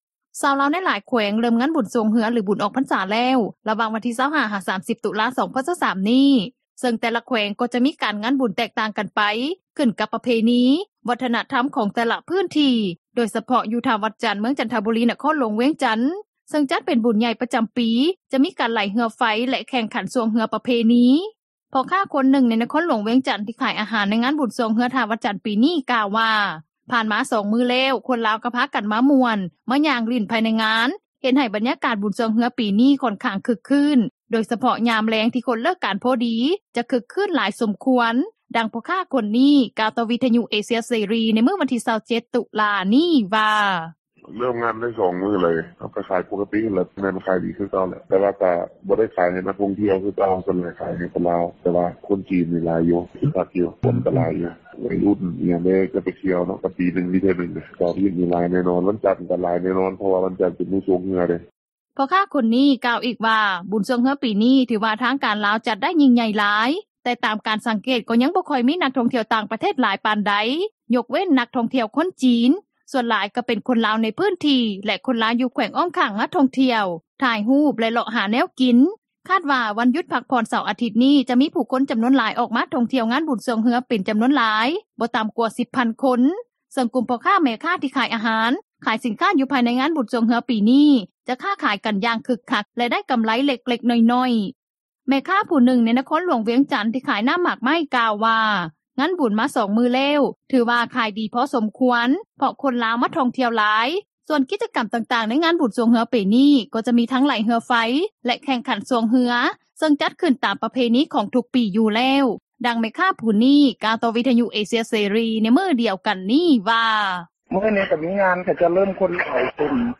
ດັ່ງພໍ່ຄ້າຄົນນີ້ ກ່າວຕໍ່ວິທຍຸເອເຊັຽເສຣີ ໃນມື້ວັນທີ 27 ຕຸລາ ນີ້ວ່າ: